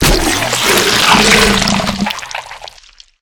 blob.ogg